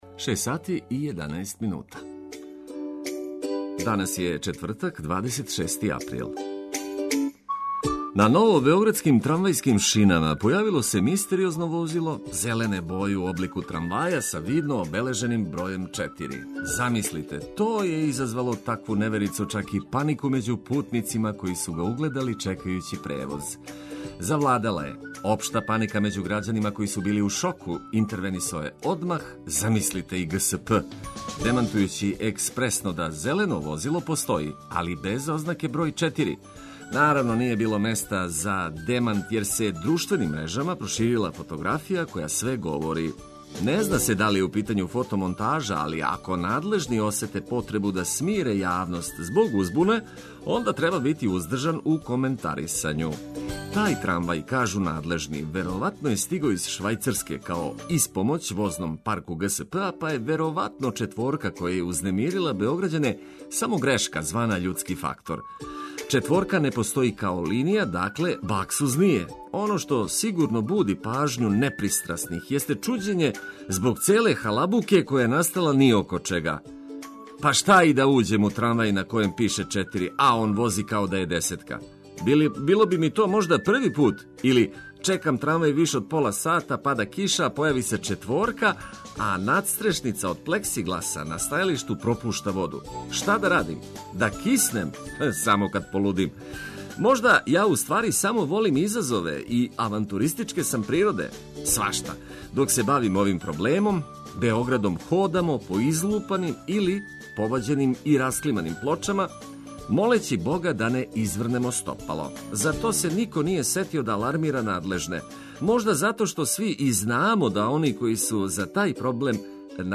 Буђење није баук ако уз ведрину музике слушате важне вести.